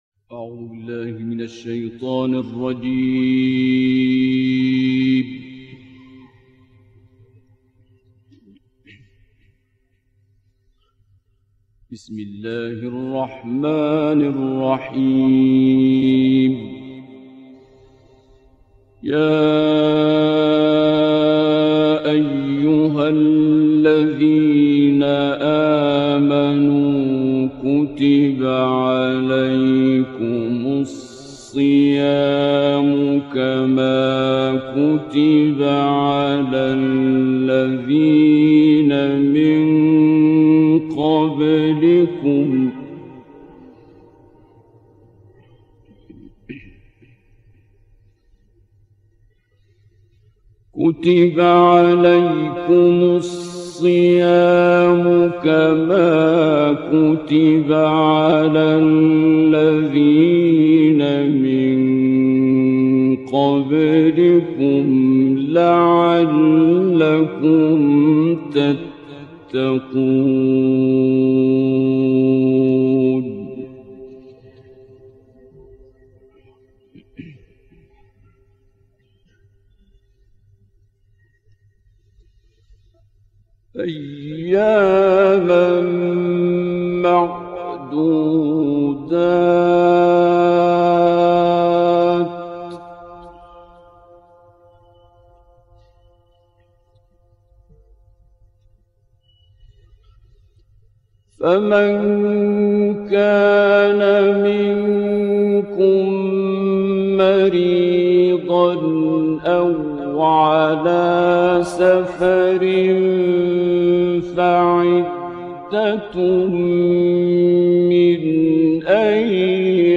تلاوت آیات صیام در باب روزه داری را توسط قاری شهیر مصری، مرحوم عبدالباسسط عبدالصمد می شنوید.